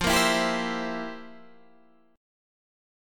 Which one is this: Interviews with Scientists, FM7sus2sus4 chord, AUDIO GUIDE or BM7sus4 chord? FM7sus2sus4 chord